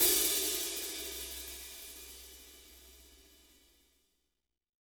-16  HAT13-R.wav